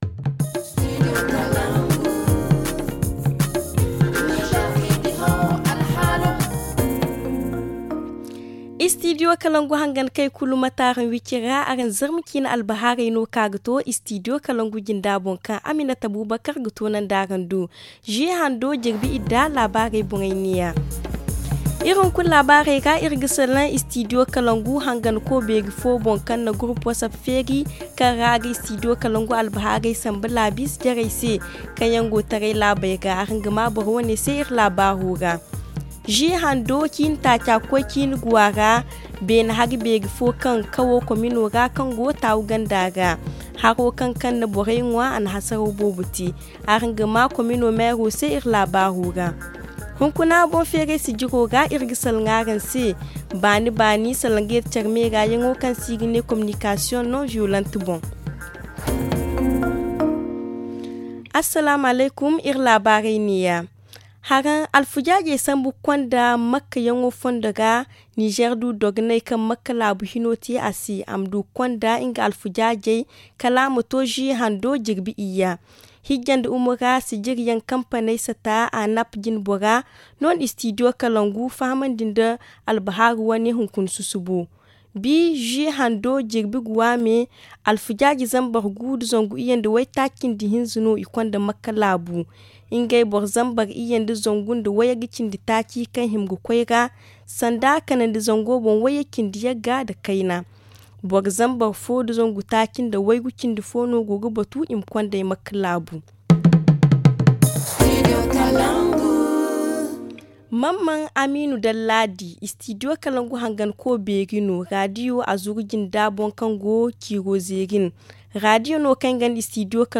Le journal du 6 juillet 2022 - Studio Kalangou - Au rythme du Niger